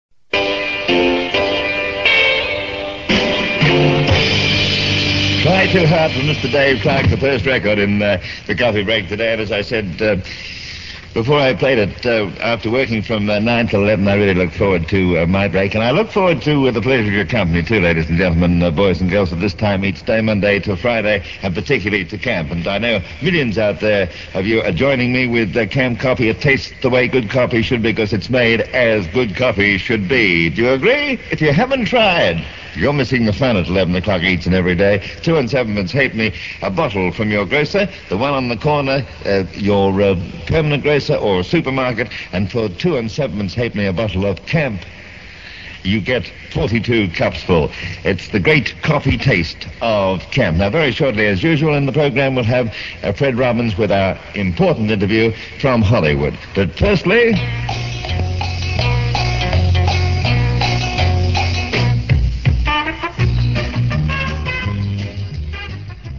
Both this and the previous clip are taken from a Radio London promotional tape for potential advertisers.